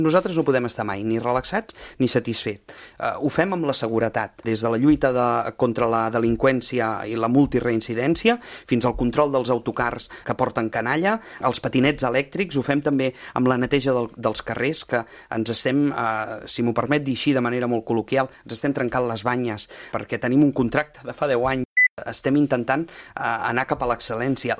Ho ha dit el portaveu de Junts a l’Ajuntament de Calella i segon tinent d’Alcaldia de Fires, Activitat cultural i Serveis municipals, Josep Grima, a l’entrevista política de RCT. En aquest sentit, ha assegurat que “s’estan trencant les banyes” per millorar la neteja i recollida de la brossa, un dels serveis que concentra més queixes ciutadanes.